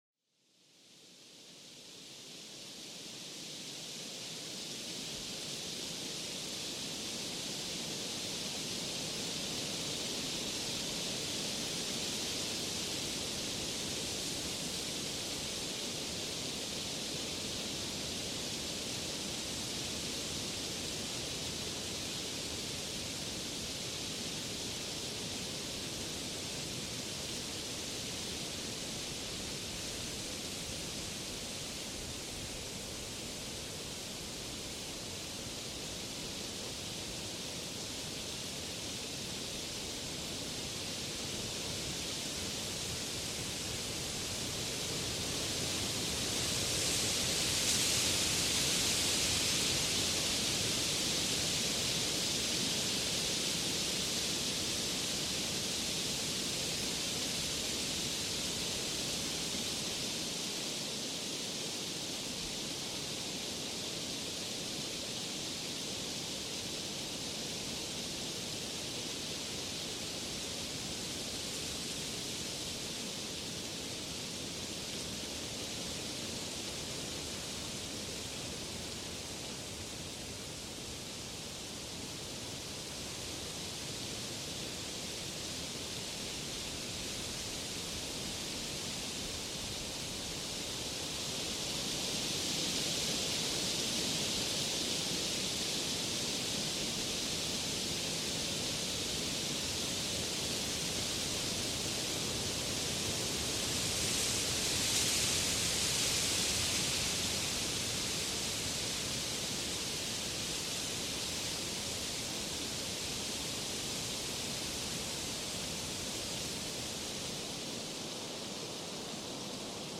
Experimenta la canción calmante del viento que se entreteje a través de las ramas frondosas. Déjate envolver por esta sinfonía natural que despierta los sentidos y tranquiliza la mente.